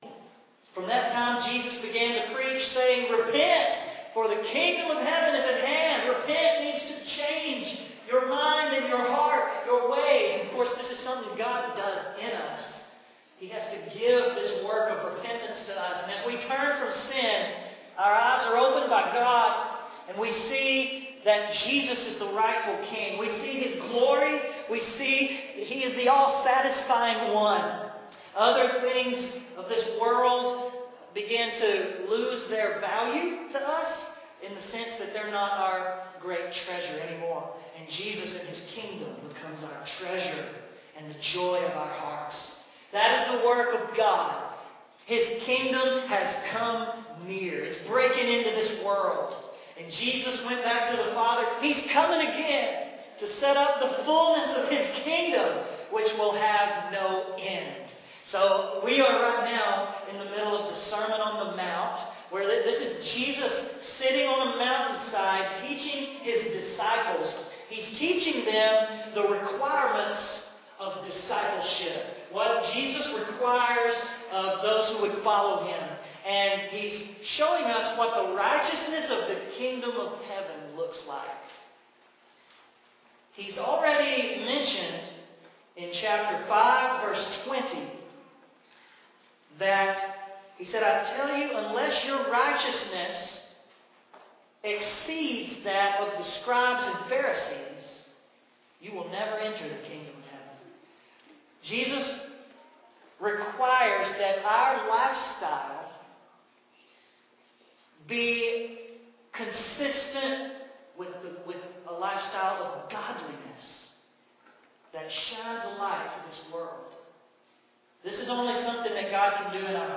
But now he warns us against doing visible righteousness with bad motives – to show off. A heart-searching message about hypocrisy, true character and having a genuine, private walk with God.